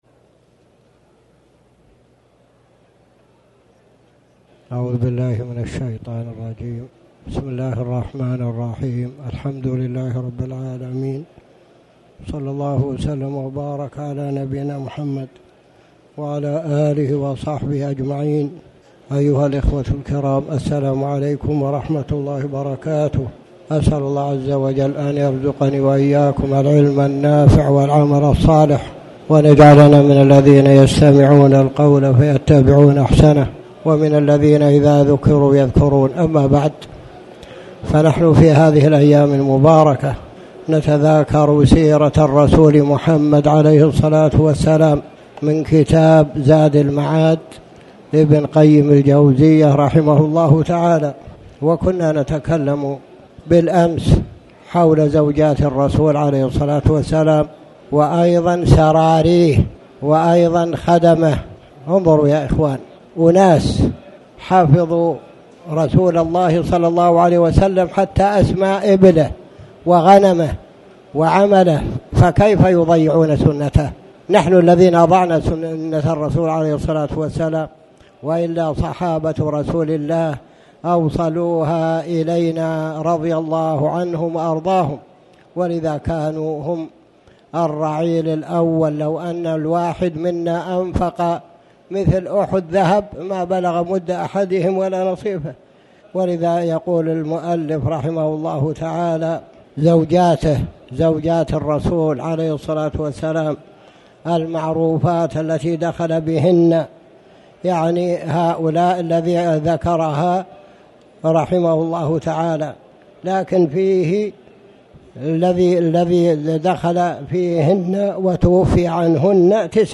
تاريخ النشر ٩ رجب ١٤٣٩ هـ المكان: المسجد الحرام الشيخ